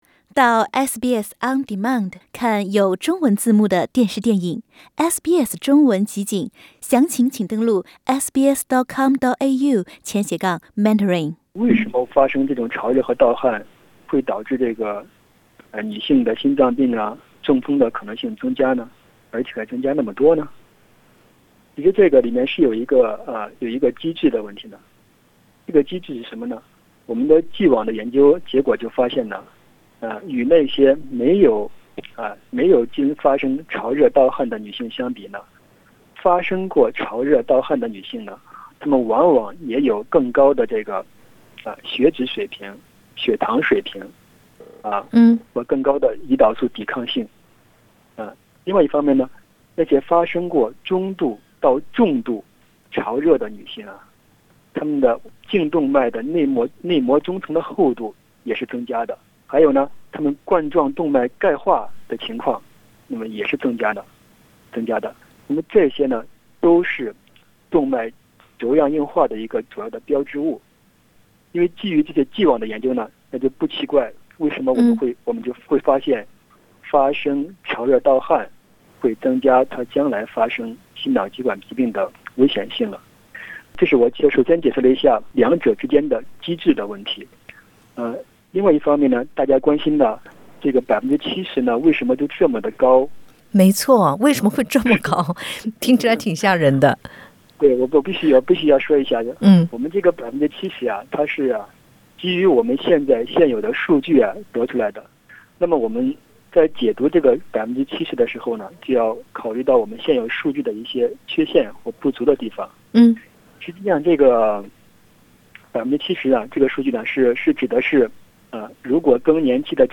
研究人员分析“患有严重血管舒缩症状的女性的非致命性心血管疾病风险是没有症状的女性的两倍以上。”（点击上图收听录音采访）